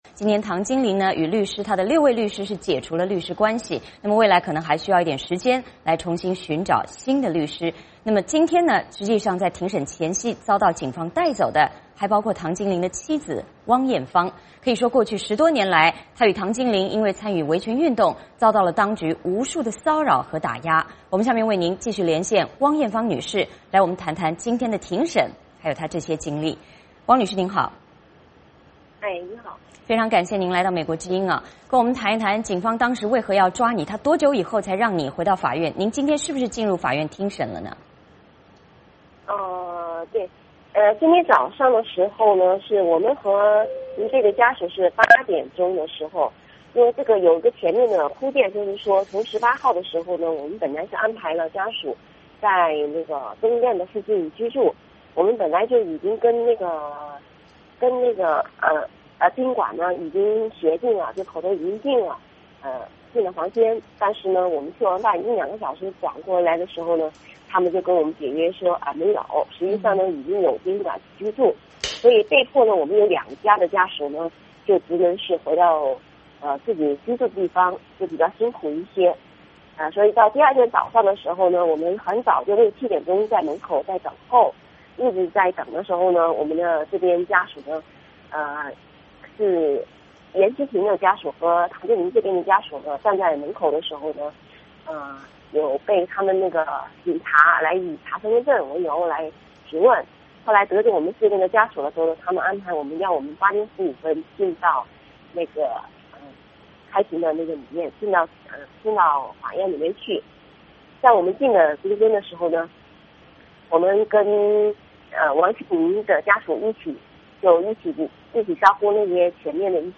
下面我们通过电话连线